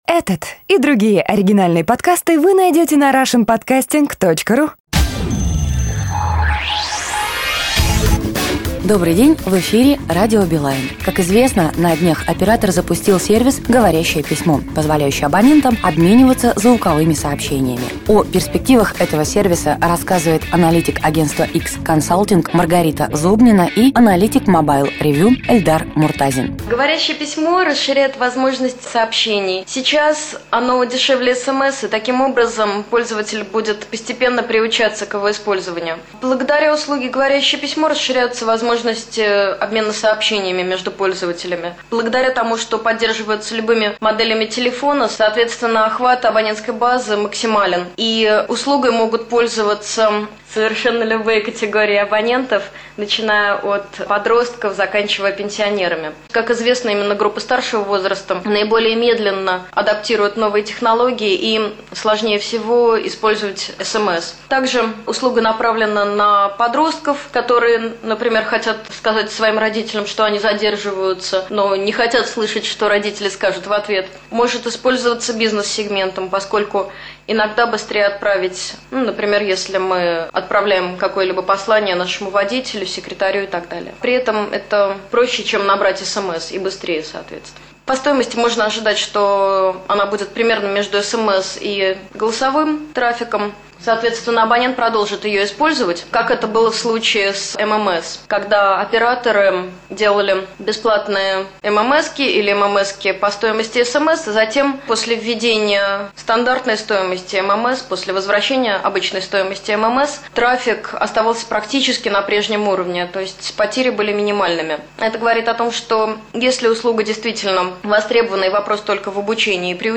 Запись беседы с экспертами, первая часть которой носит следы спешного монтирования из множества кусочков, можно